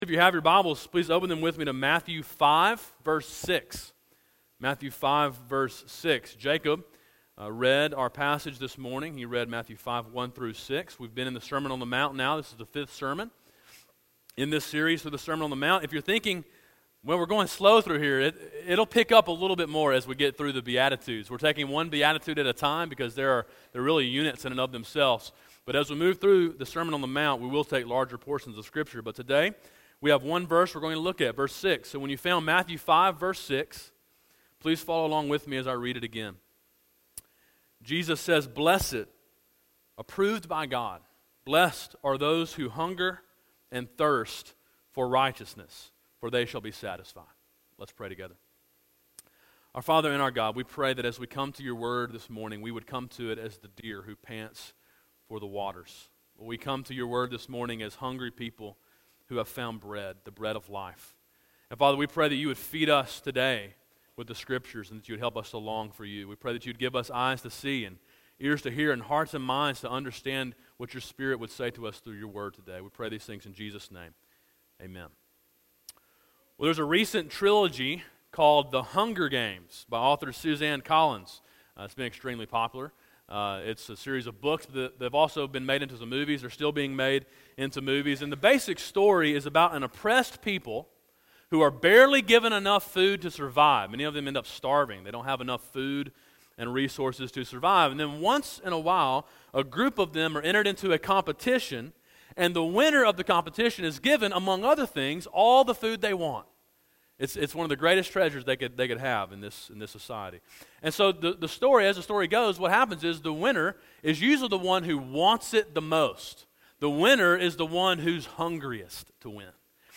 A sermon in a series titled The Sermon on the Mount: Gospel Obedience.